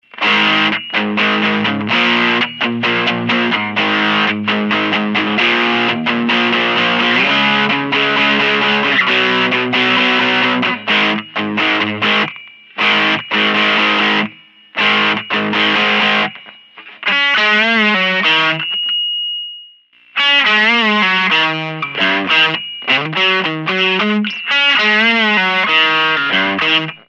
Full Drive Sounds
Gain is set near maximum to demonstrate the full tilt boogie. Volume is loud enough that the setup is trying to feedback.
All clips recorded through my AX84 tube amp set for a clean neutral tone using a stock 1970 Fender Stratocaster - miked with an SM-57. All distortion is being produced by the pedal.